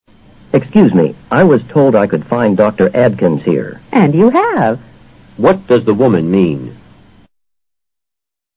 托福听力小对话【97】